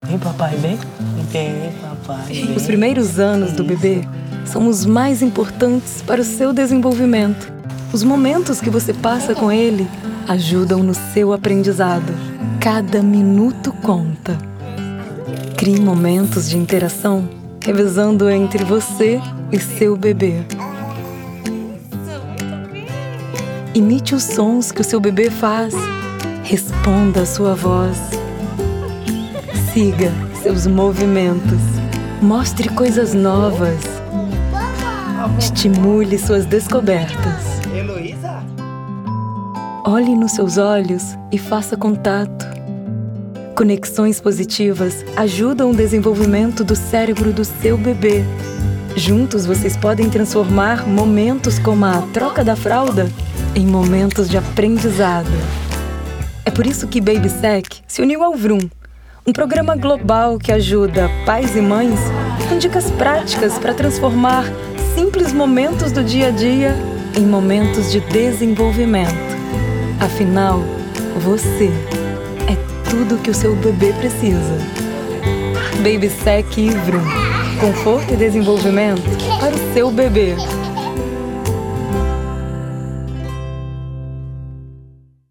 Voz com um leve “rouquinho” pra deixar seu anuncio com um toque único, trazendo mais sofisticação e visibilidade no mercado publicitário.